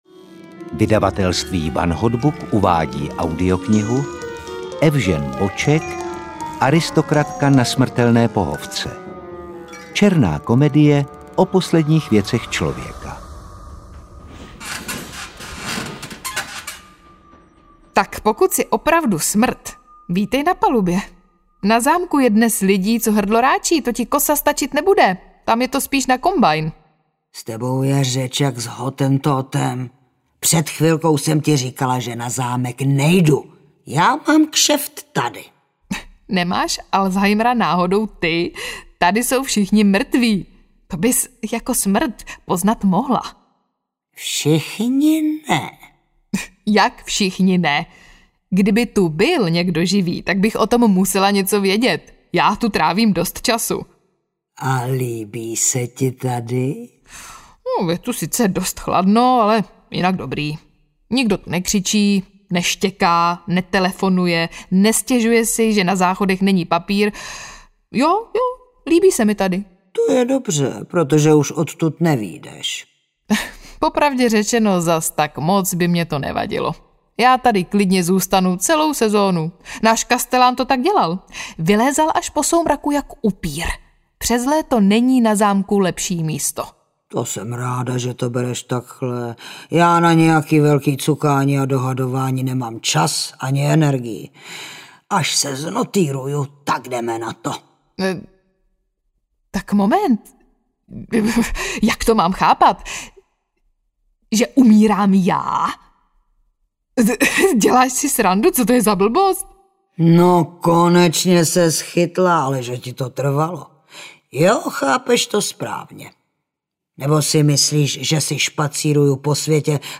Aristokratka na smrtelné pohovce audiokniha
Ukázka z knihy
Před otravně zvonícím smartphonem a slaboduchými projevy mladé generace se očividně nelze schovat ani v poslední zbývající hodince!Černá komedie v parádním dramatizovaném podání.